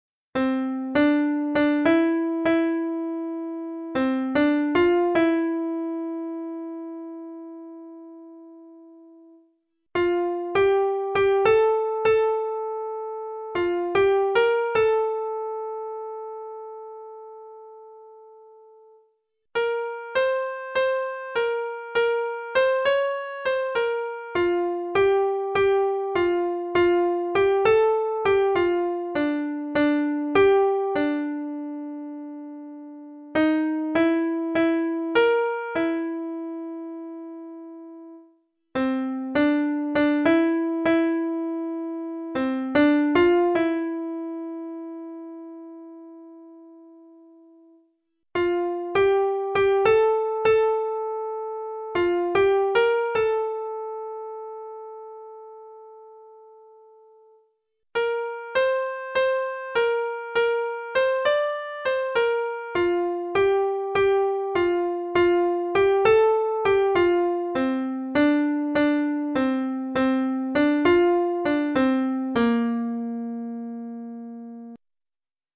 File MIDI